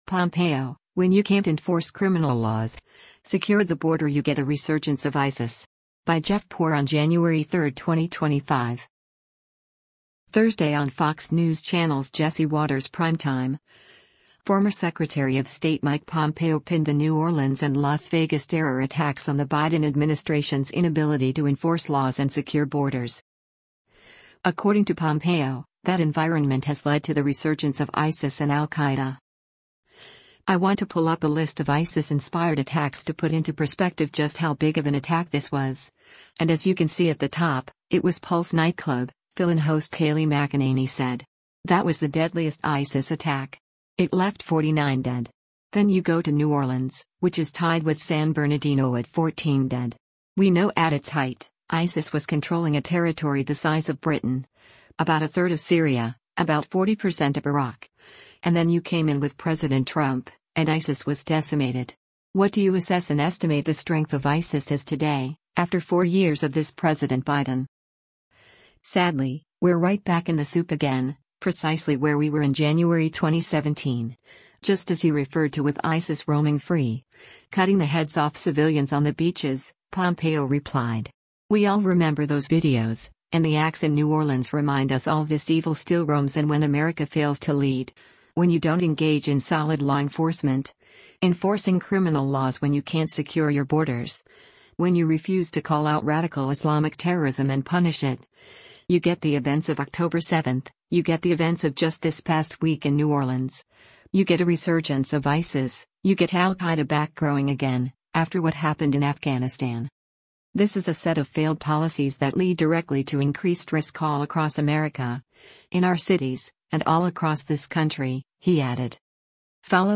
Thursday on Fox News Channel’s “Jesse Watters Primetime,” former Secretary of State Mike Pompeo pinned the New Orleans and Las Vegas terror attacks on the Biden administration’s inability to enforce laws and secure borders.